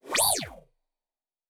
pgs/Assets/Audio/Sci-Fi Sounds/Movement/Synth Whoosh 2_3.wav at master
Synth Whoosh 2_3.wav